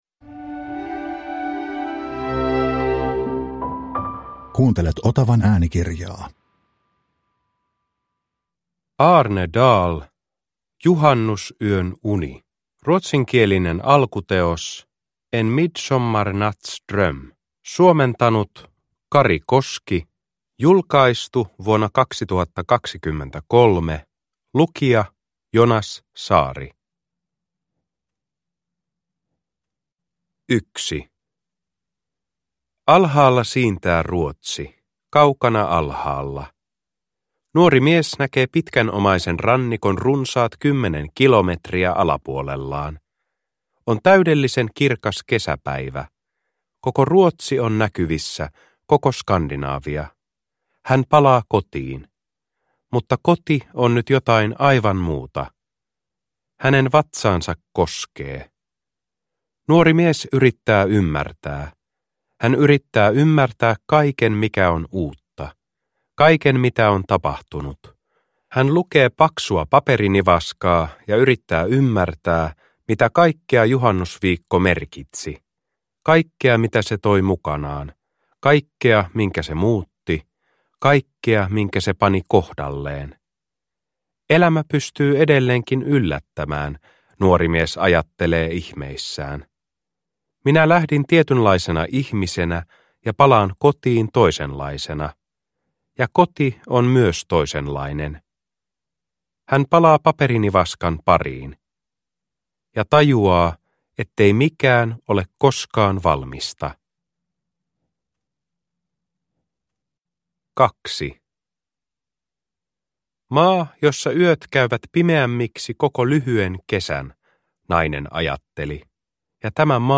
Juhannusyön uni – Ljudbok – Laddas ner